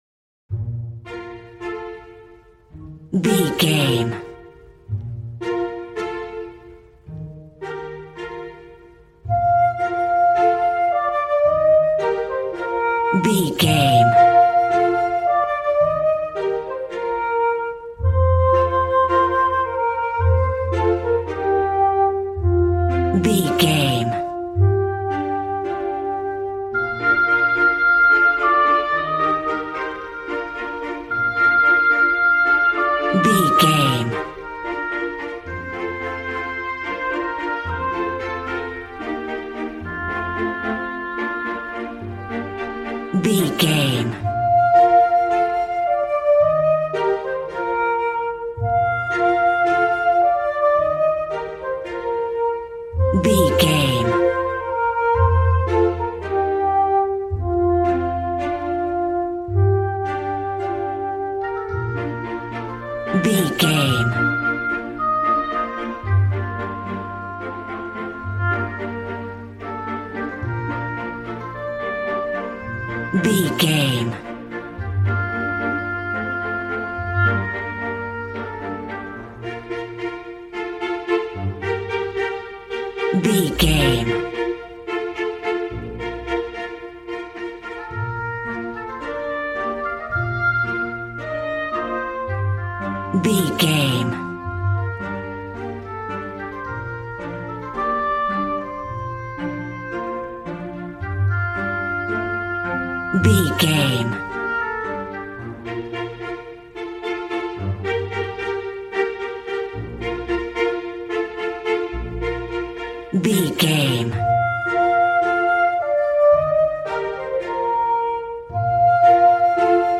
A warm and stunning piece of playful classical music.
Regal and romantic, a classy piece of classical music.
Ionian/Major
regal
piano
violin
strings